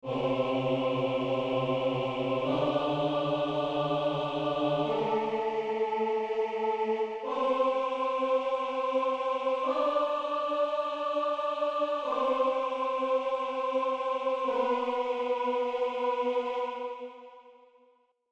En-octavas.mp3